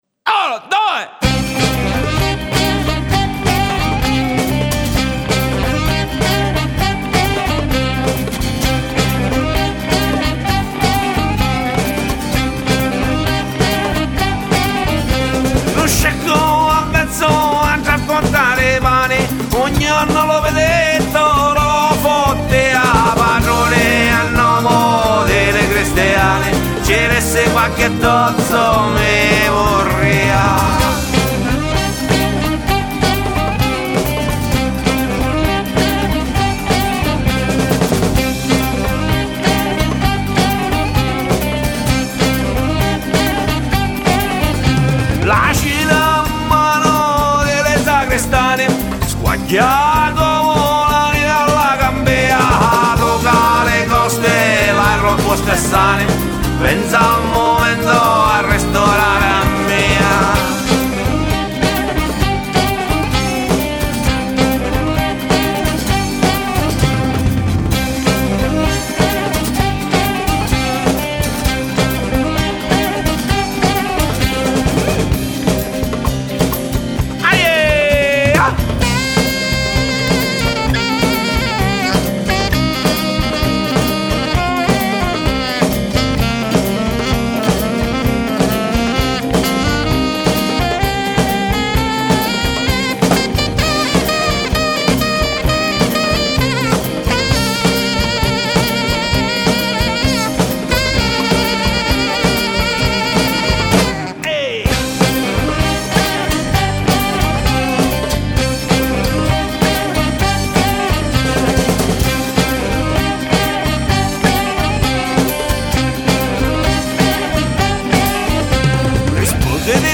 La band di world music